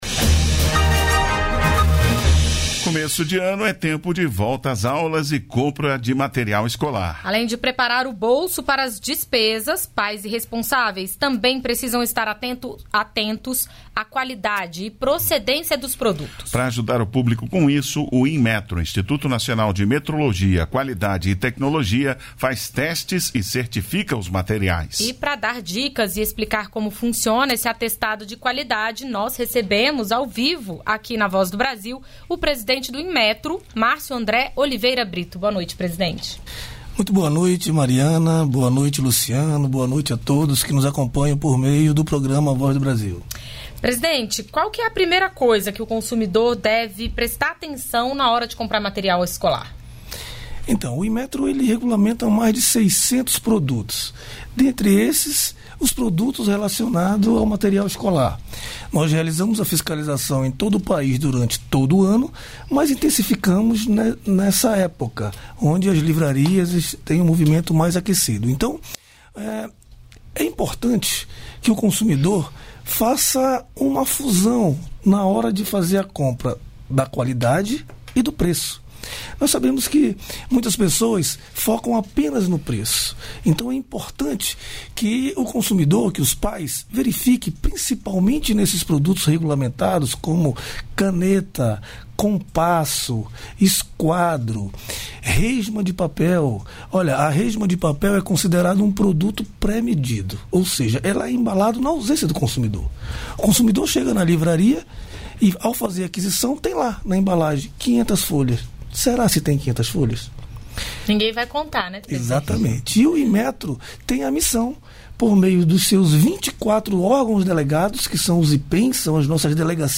O ministro de Portos e Aeroportos falou sobre o programa Voa Brasil que garantirá passagens aéreas mais baratas a aposentados do INSS.
Entrevistas